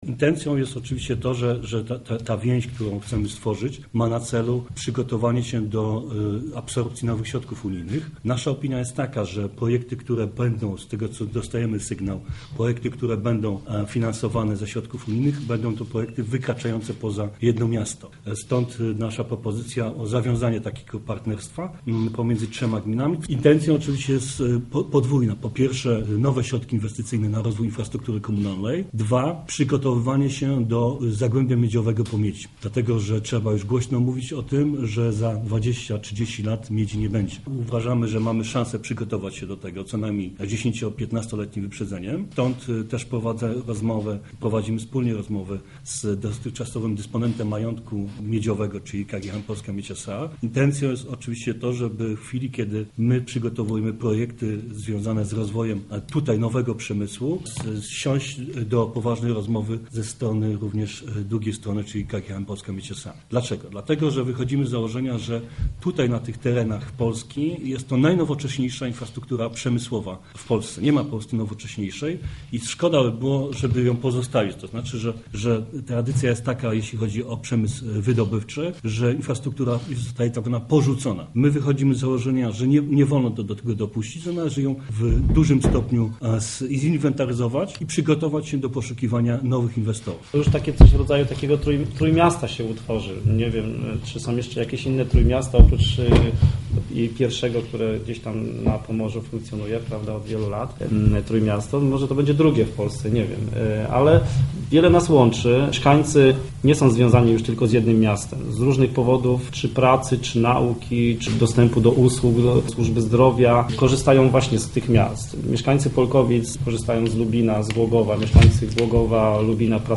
Mówią w kolejności: prezydent Lubina, Robert Raczyński, burmistrz Polkowic, Wiesław Wabik i prezydent Głogowa, Jan Zubowski.